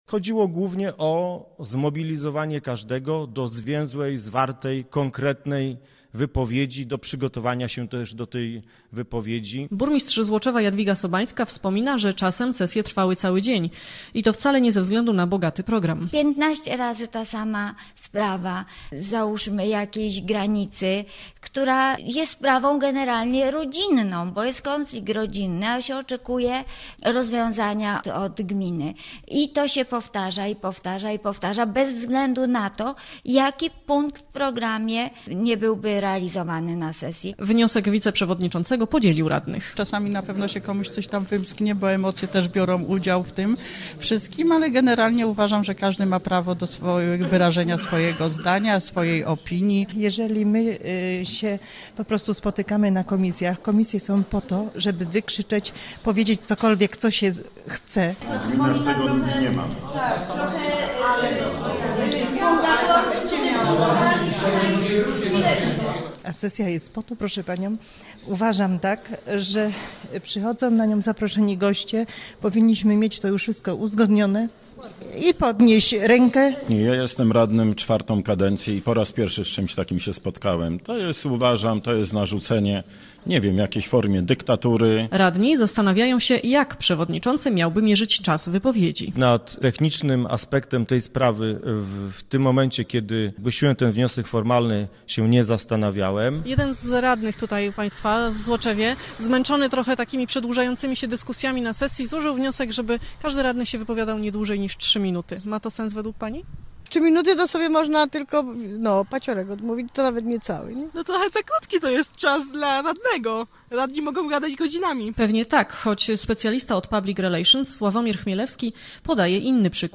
O szczegółach w relacji naszej reporterki: Nazwa Plik Autor Skrócenie czasu wystąpień radnych audio (m4a) audio (oga) Warto przeczytać Lionel Richie zaśpiewał w łódzkiej Atlas Arenie.